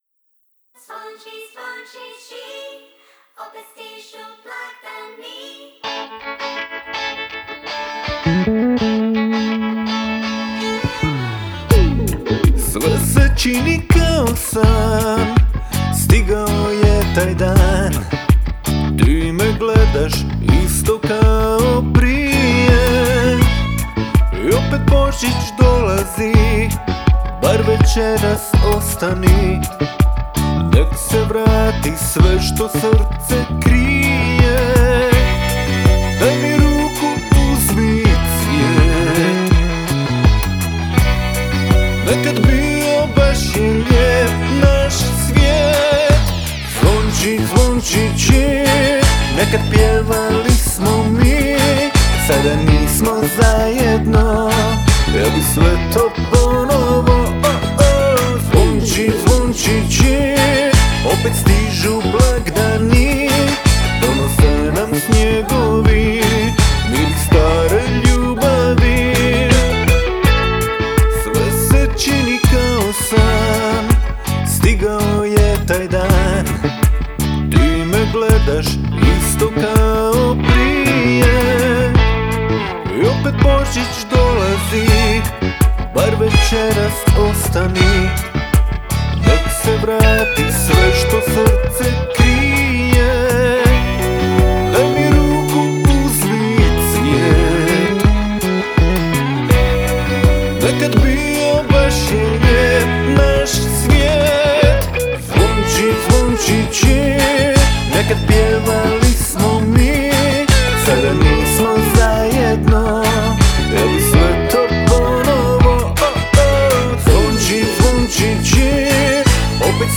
Žanr Pop